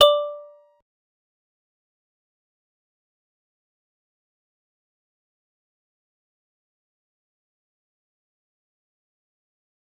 G_Musicbox-D5-pp.wav